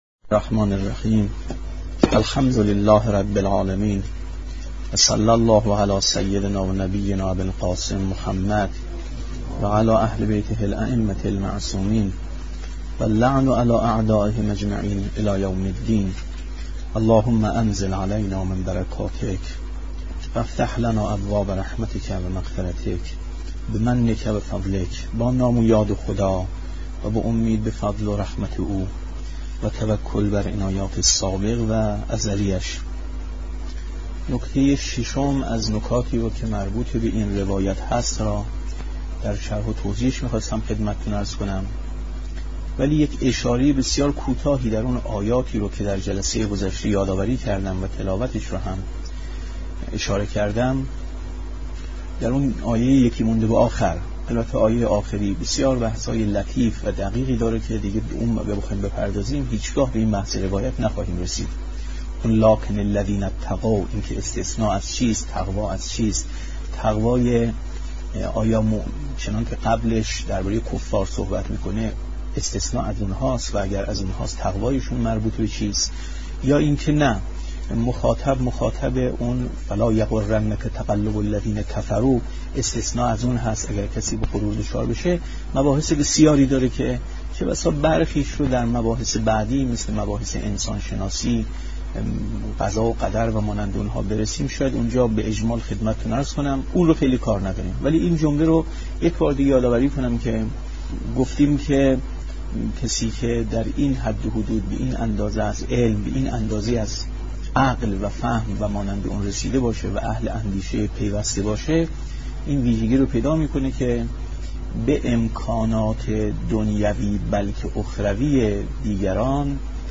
شرح حدیث عنوان بصری ـ درس چهارم ـ 6/ 4/ 1381